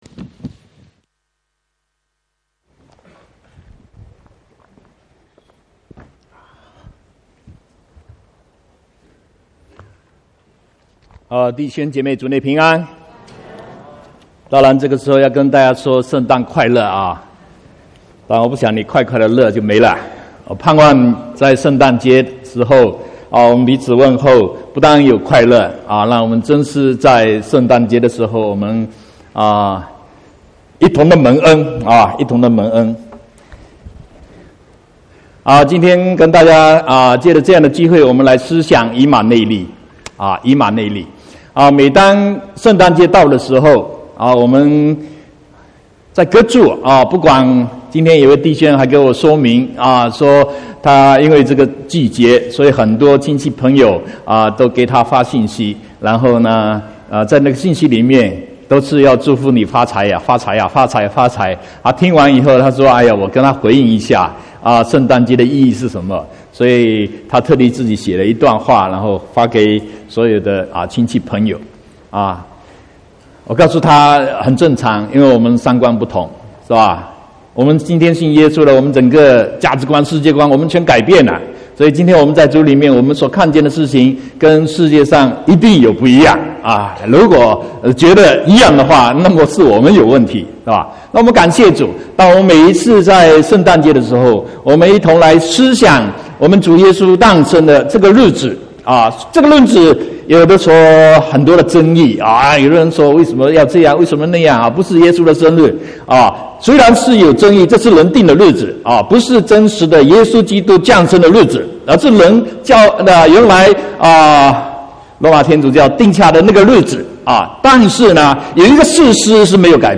25/12/2018 國語堂聖誕節講道